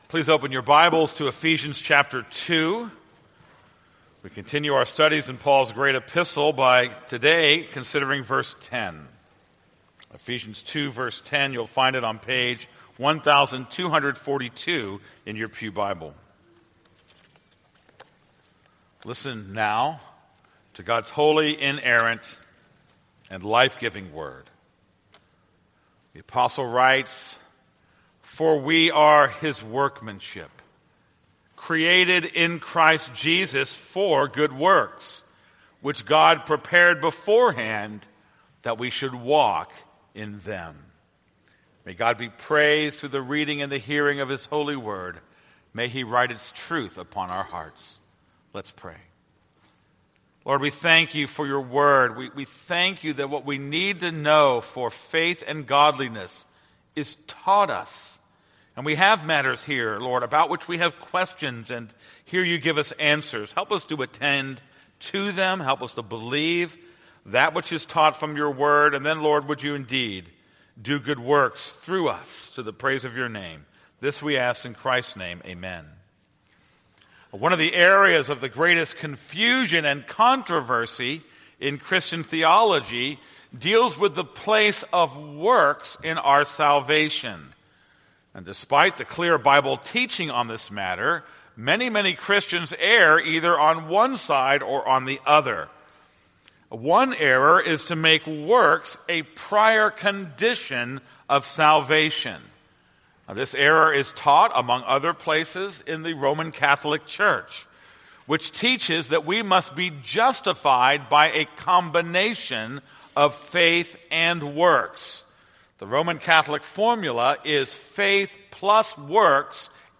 This is a sermon on Ephesians 2:10.